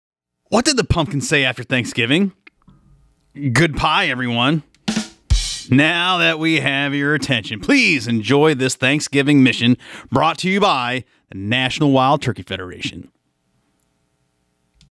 Gobbling for the Holidays features a fun welcome audio message.